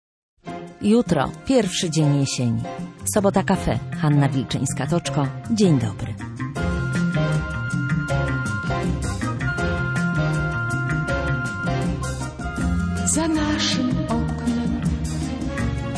mówi Magda Umer w rozmowie